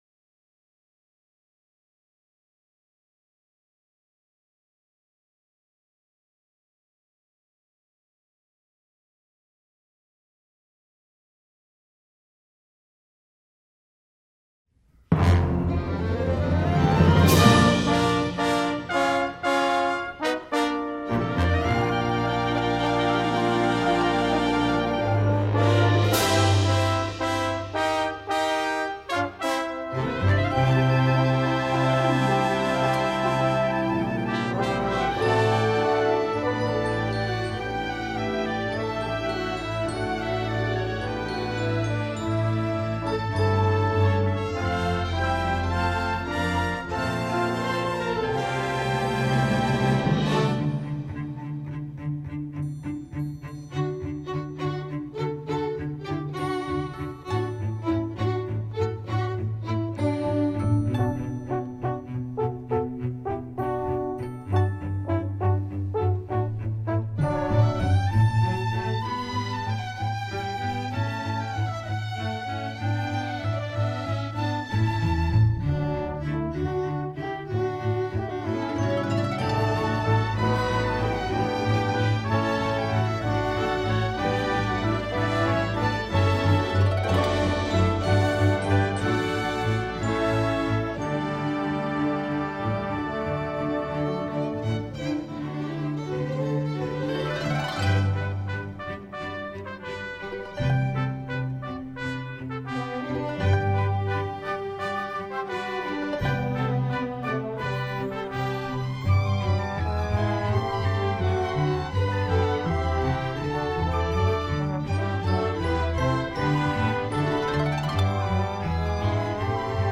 Christmas Concert 2019